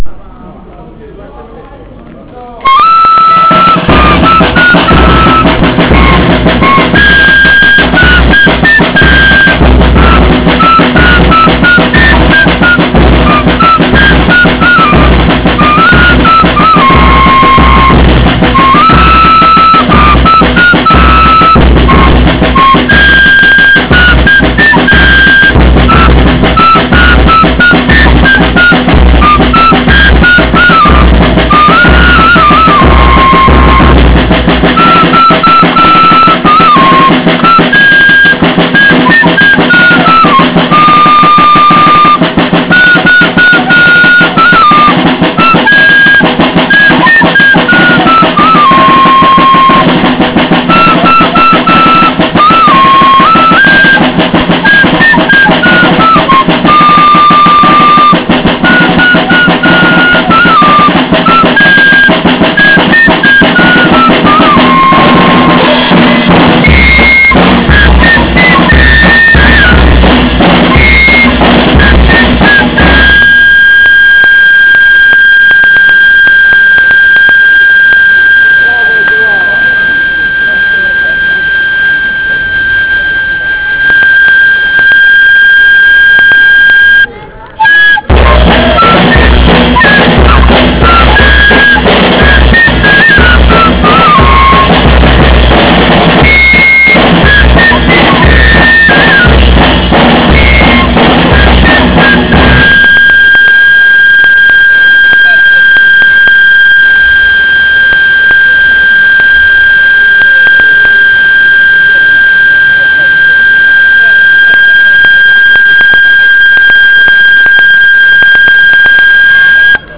Ascolta il tamburo di san Vittoriano